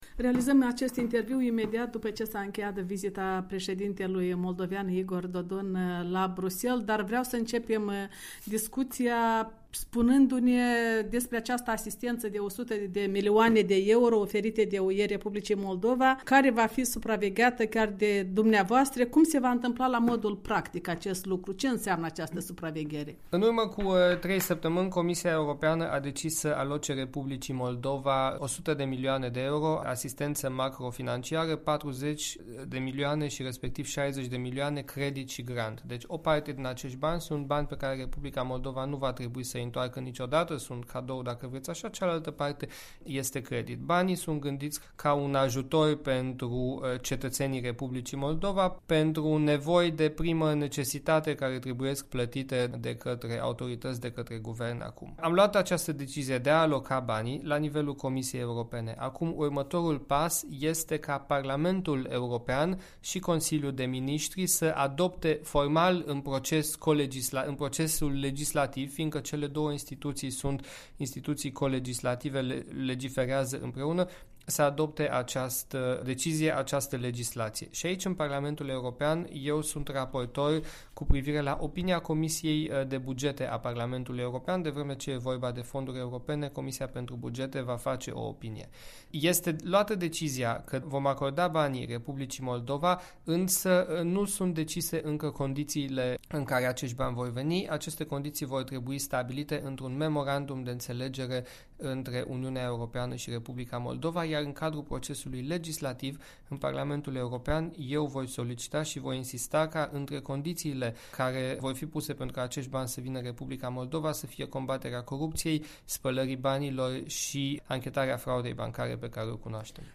Un interviu cu eurodeputatl Siegfried Mureșan